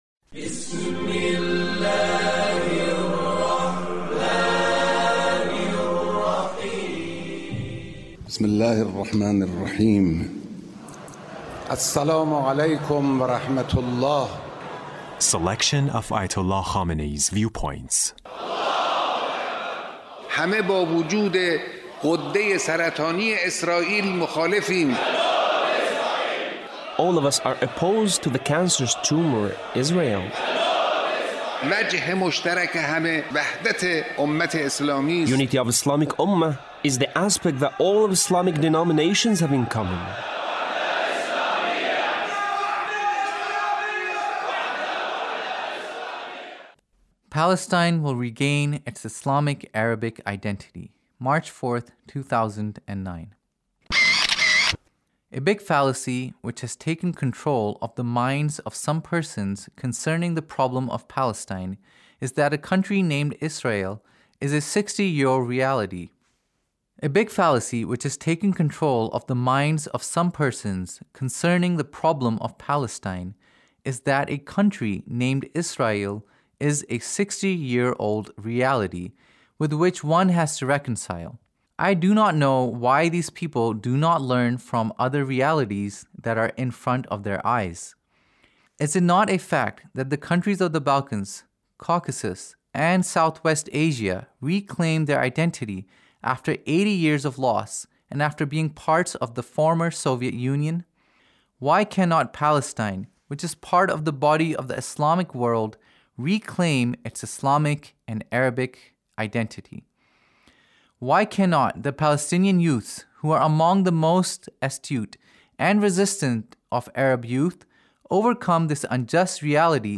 Leader's Speech (1878)